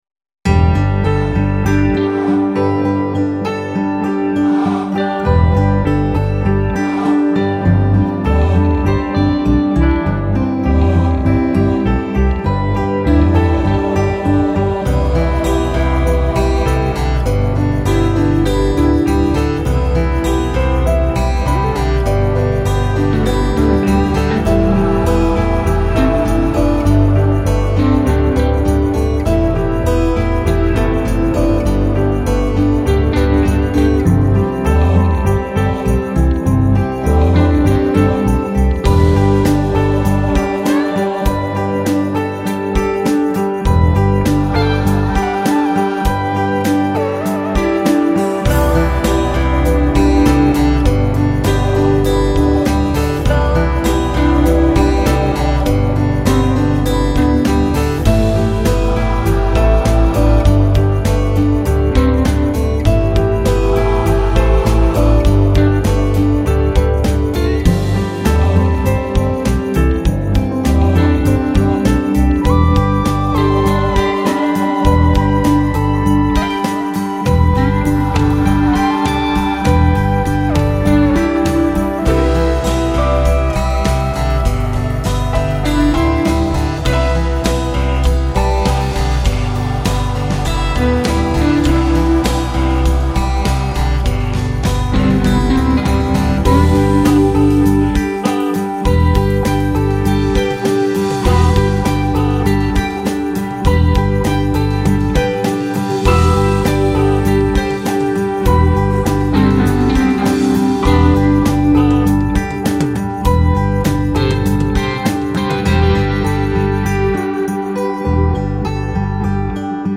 gimmick - guitare - funky - ete - vacances